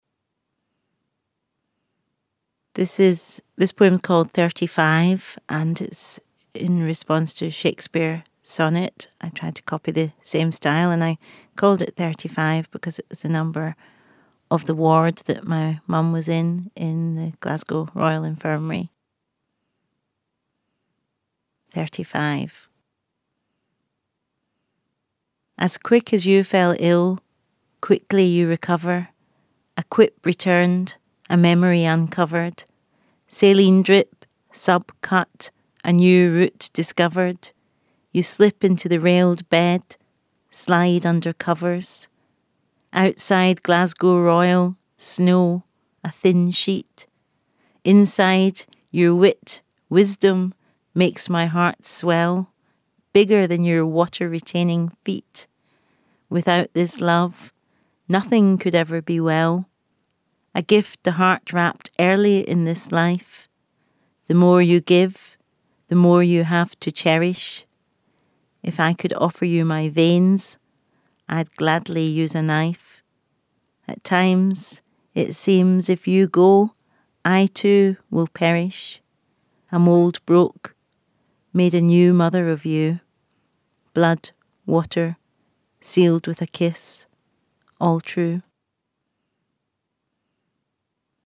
The Shakespeare 400 Collection contains recordings of twenty sonnets read by ten major poets. Each poet has chosen a favourite sonnet by Shakespeare and, inspired by that sonnet, has written a new sonnet of their own.
Shakespeare-400-Collection-Thirty-Five-by-Jackie-Kay.mp3